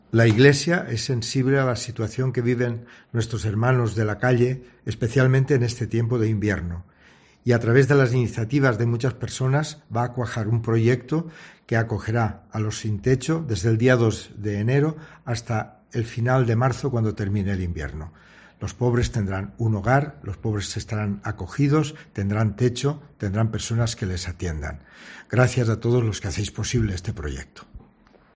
El obispo de la Diócesis de Huelva, José Vilaplana, analiza este proyecto que arrancará el próximo 2 de enero y que atenderá a las personas sin hogar.